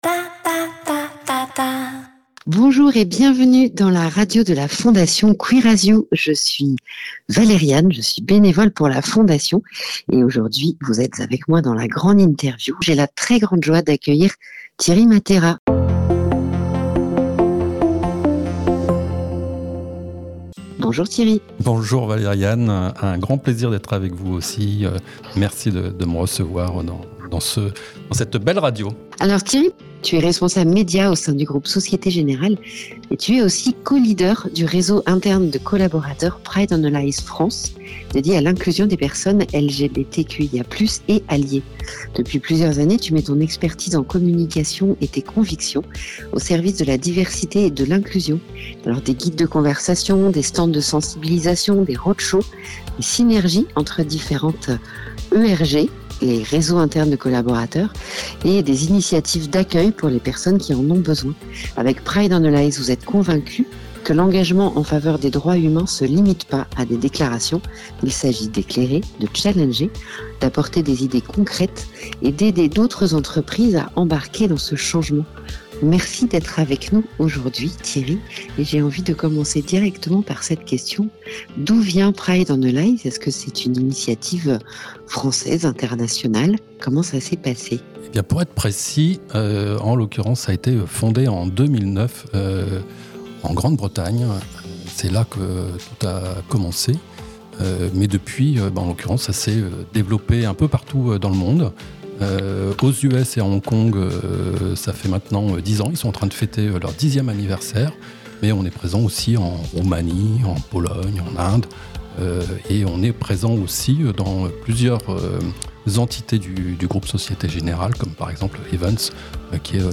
La grande interview